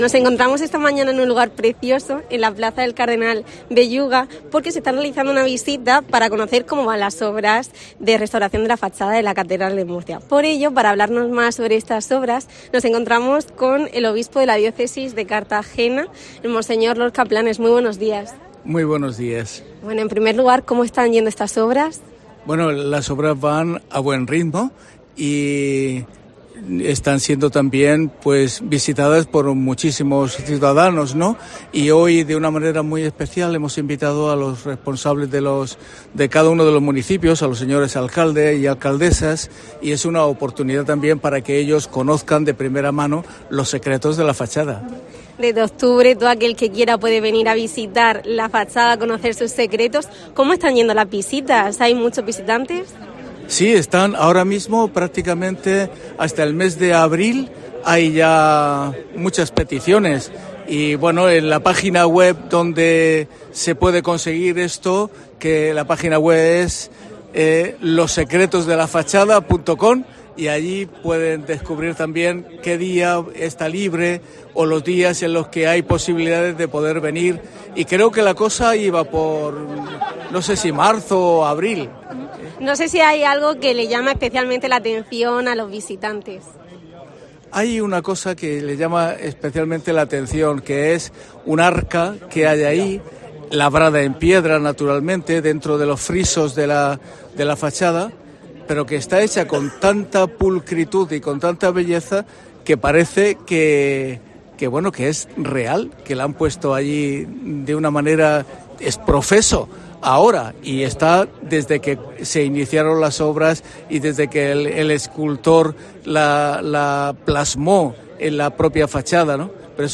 El obispo de la Diócesis de Cartagena, Don José Manuel Lorca Planes, ha explicado para COPE cómo van las obras de restauración de la fachada de la Catedral de Murcia.